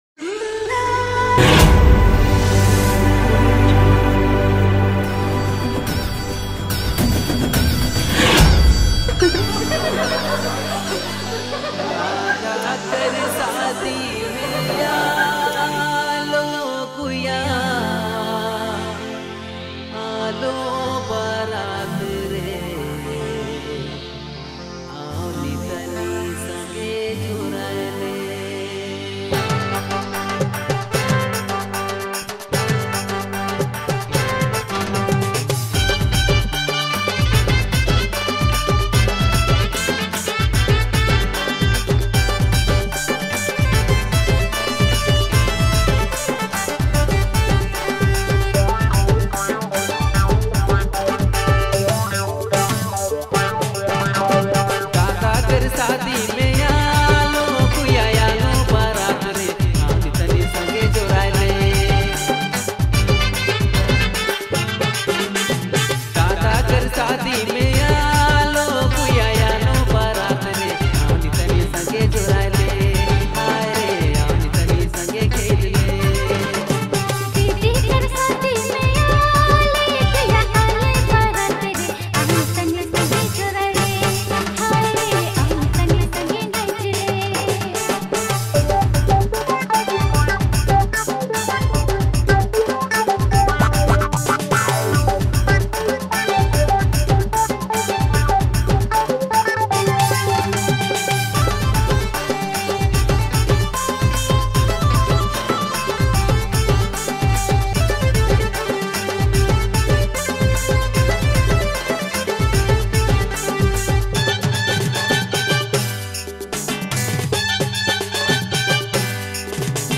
Dj Remixer
New Latest Nagpuri Song